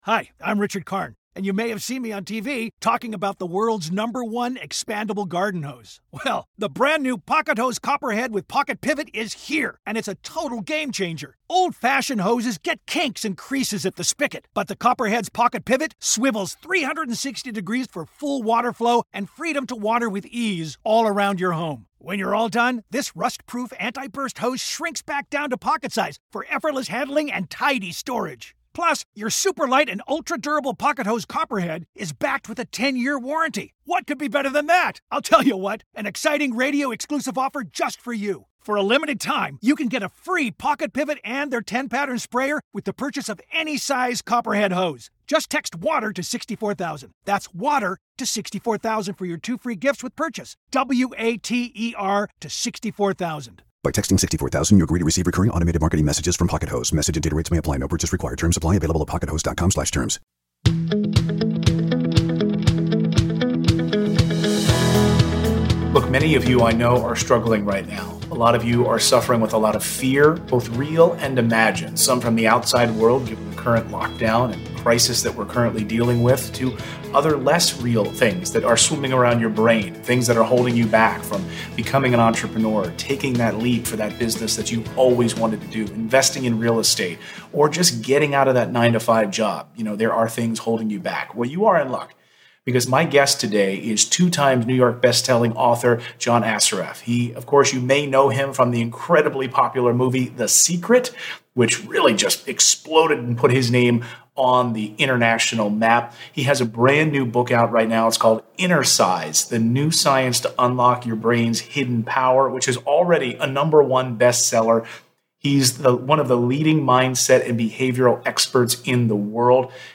In this interview, John is sharing life changing secrets from his newest book, Innercise.